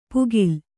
♪ pugil